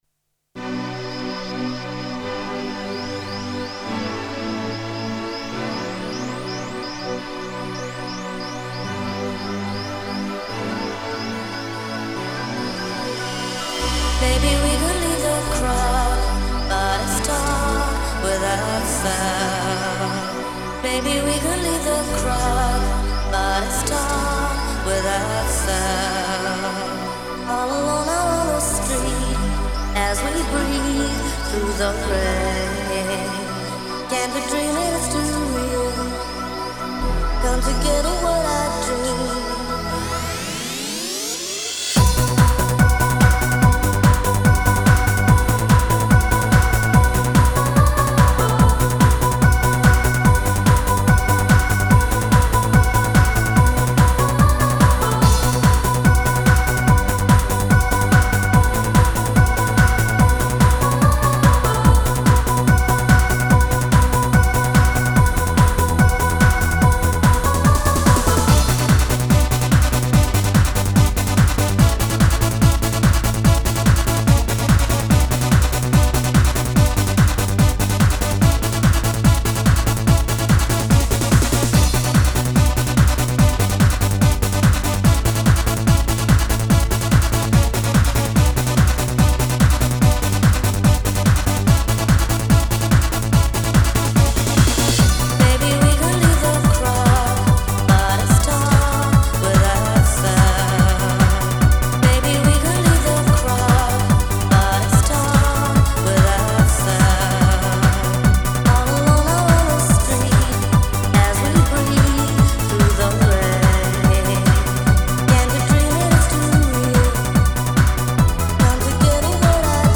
Genre: Progressive Trance.